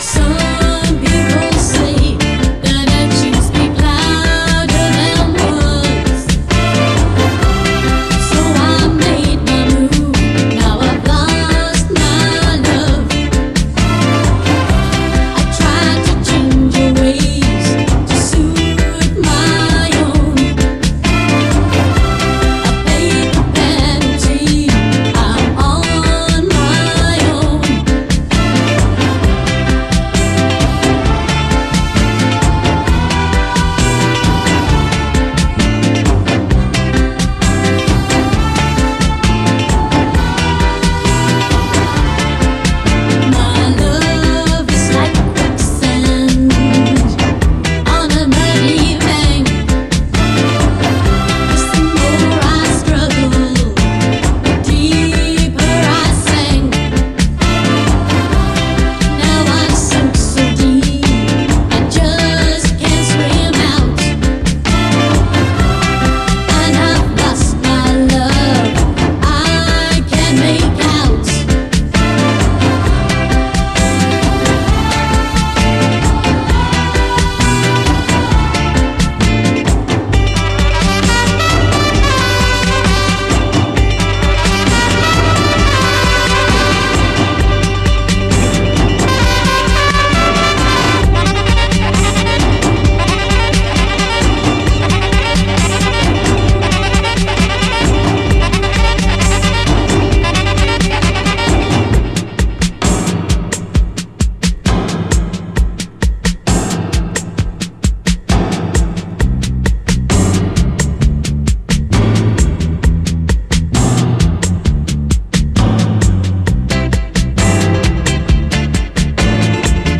80's～ ROCK, REGGAE, ROCK
隠れたガーリー・ステッパー・レゲエ〜UKラヴァーズ、チープなニューウェーヴ・ファンク！
ダビーなディレイ処理とピアノの配し方がオシャレです。